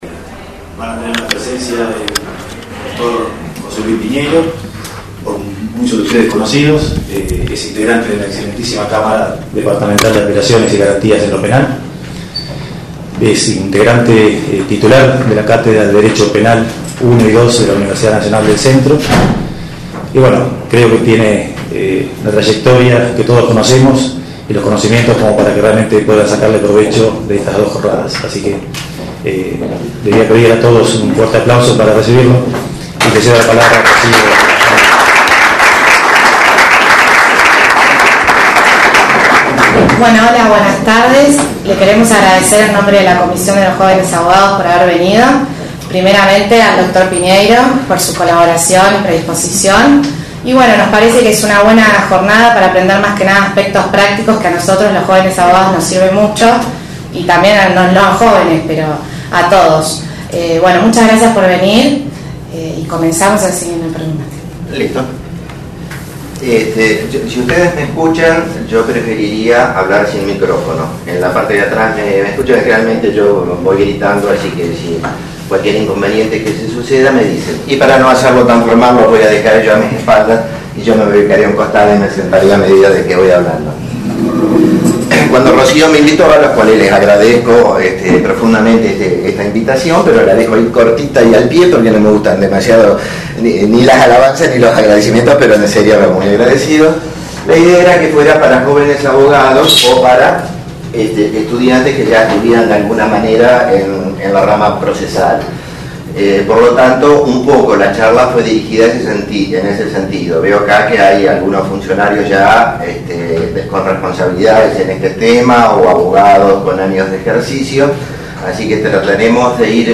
Tuvieron lugar en la sede del Colegio Abogados del Departamento Judicial de Azul de 14.30 a 18.30 hs.
Audio 1 Disertante: Dr. José Luis Piñeiro, magistrado de la Cámara de Apelaciones y Garantías en lo Penal del Departamento Judicial de Azul y docente de Derecho Penal I y II de la UNICEN. Temas: Medidas de coerción, Prisión Preventiva y Excarcelación.